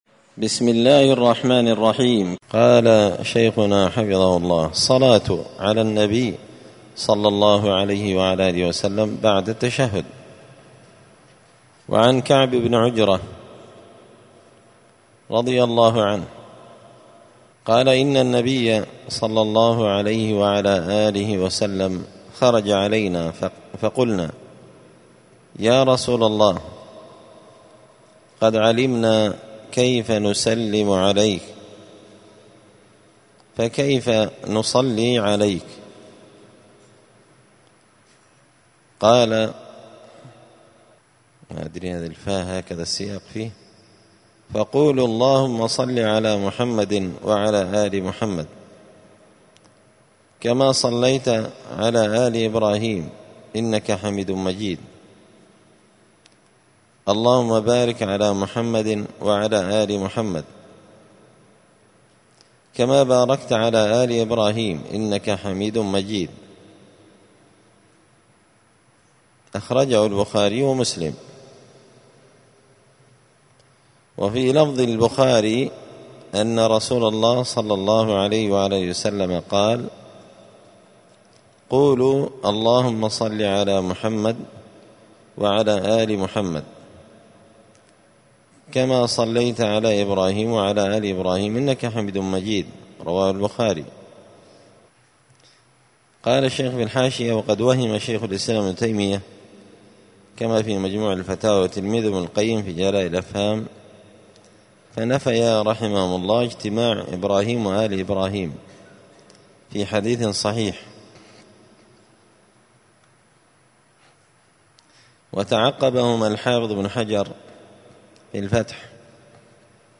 *{الدرس الخامس والثلاثون (35) أذكار الصلاة الصلاة على النبي بعد التشهد}*